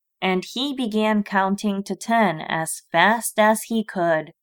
英語の朗読ファイル